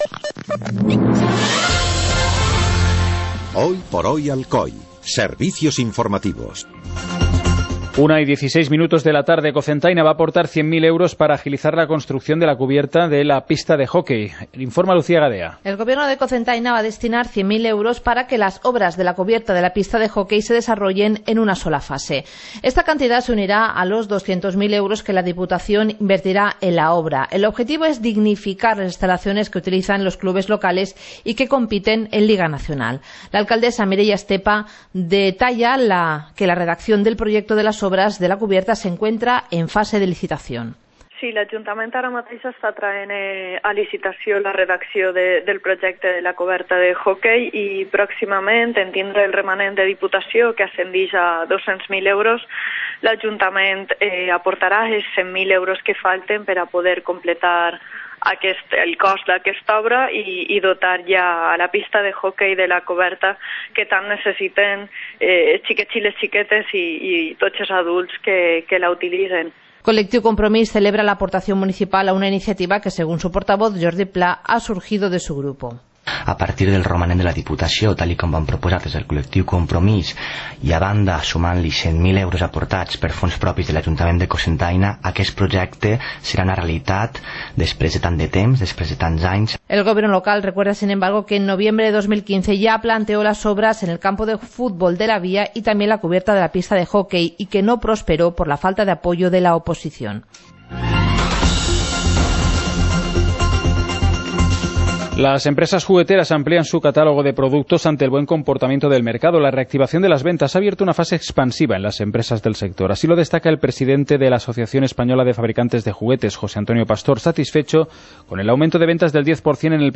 Informativo comarcal - lunes, 06 de junio de 2016